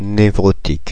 Ääntäminen
Synonyymit névrosé (lääketiede) fou irritable Ääntäminen Paris: IPA: [ne.vʁɔ.tik] France (Paris): IPA: /ne.vʁɔ.tik/ Haettu sana löytyi näillä lähdekielillä: ranska Käännöksiä ei löytynyt valitulle kohdekielelle.